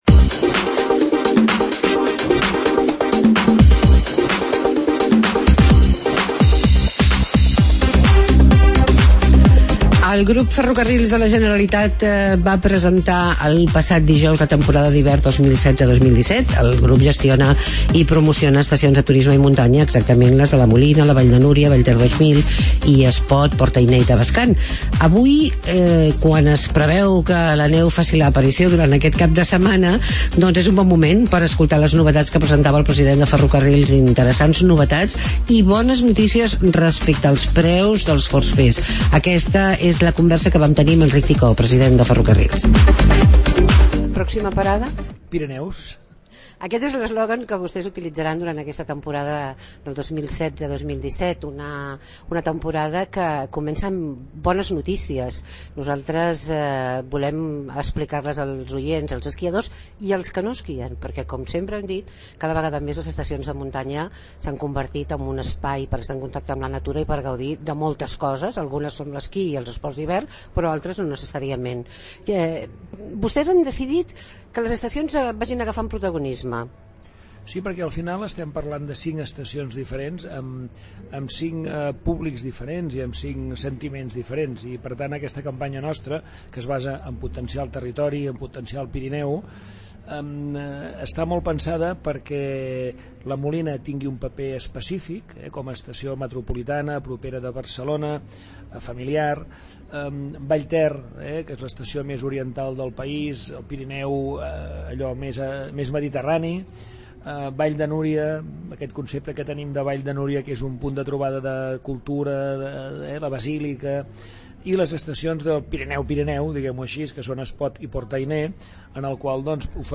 Presentació Temporada FGC ENTREVISTA ENRIC TICÓ.